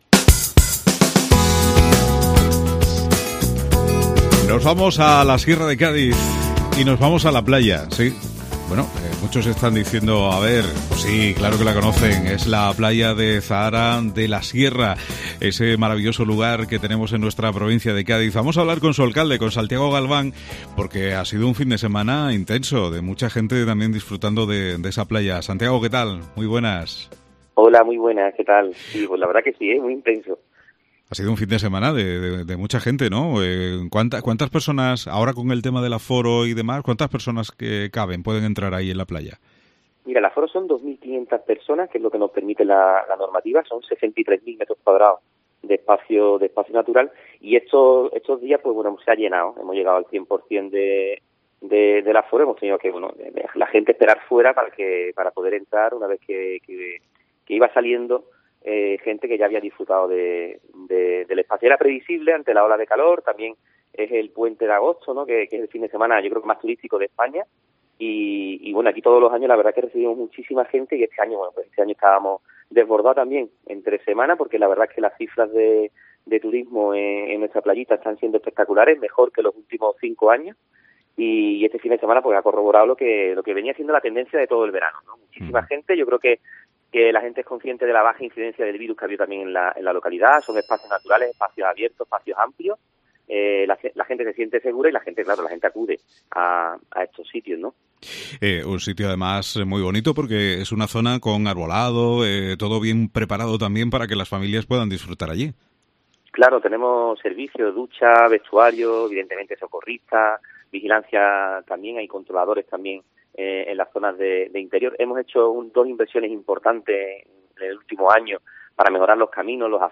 Santiago Galván, Alcalde de Zahara de la Sierra - La playita y el incremento del turismo rural